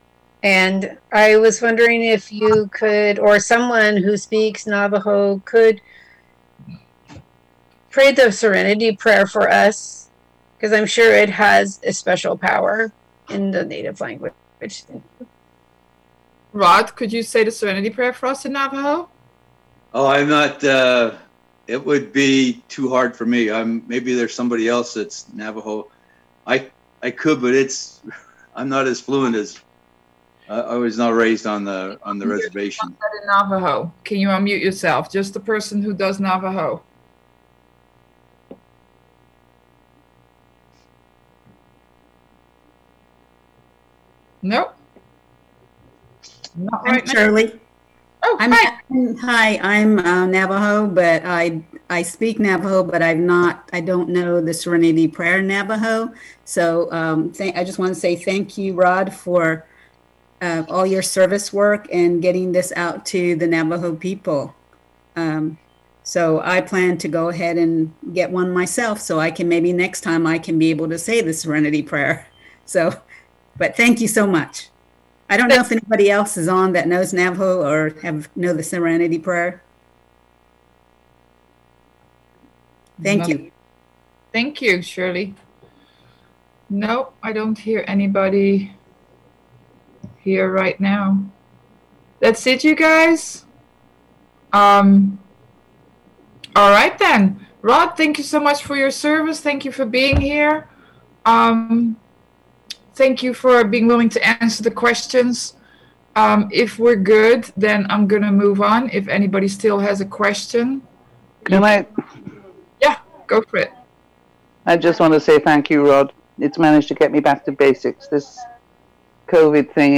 American Indian Conference - AWB Roundup Oct 17-18